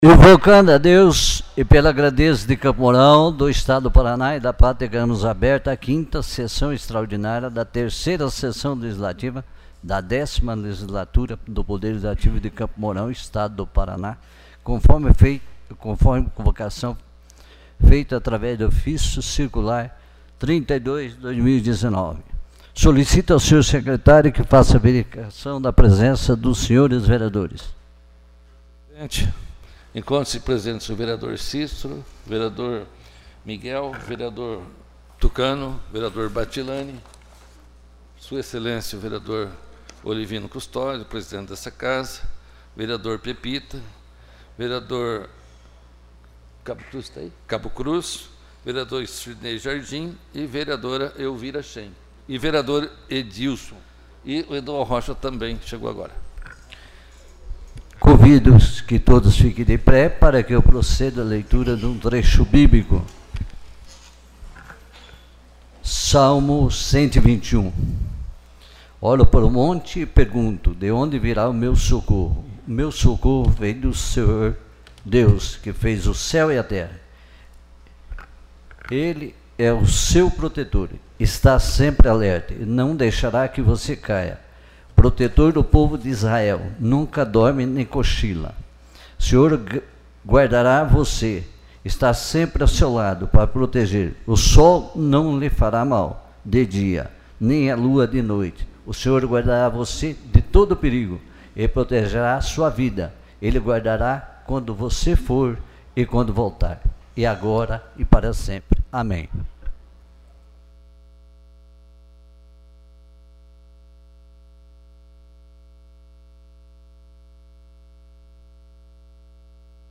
5ª Sessão Extraordinária